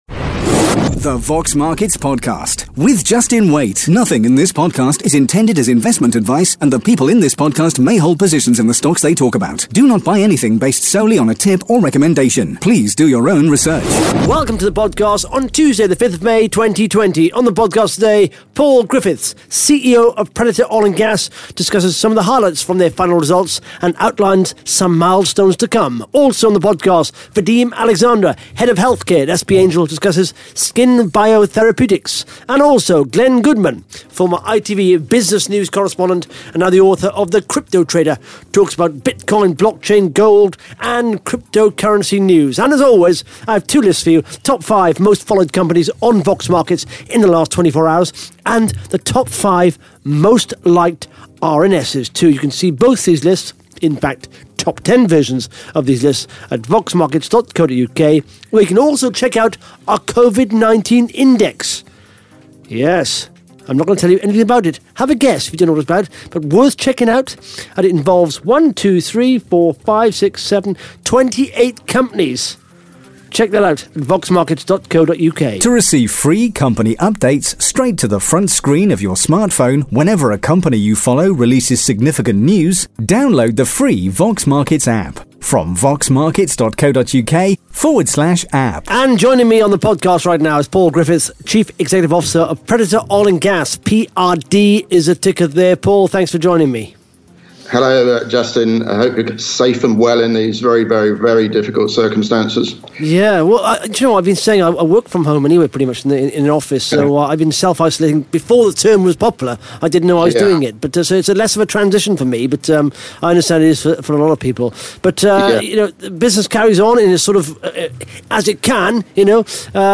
Interview starts at 17 minutes 50 seconds